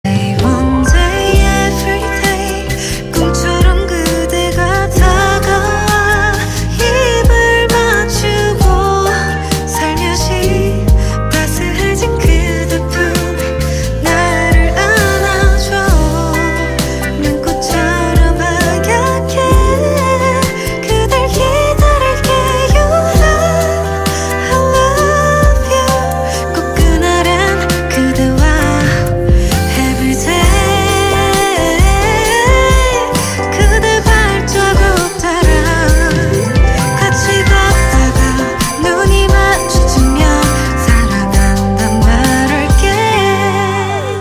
Thể loại nhạc chuông: Nhạc hàn quốc